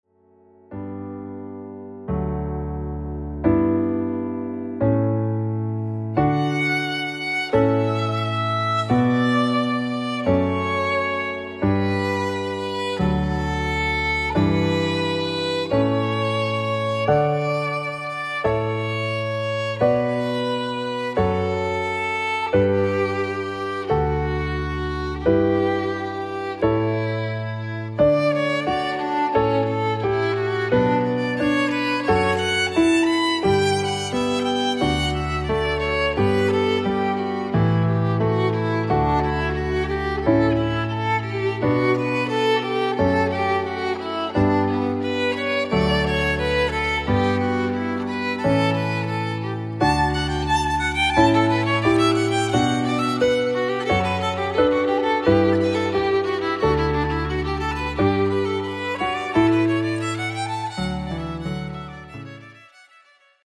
Midlands Based Solo Violinist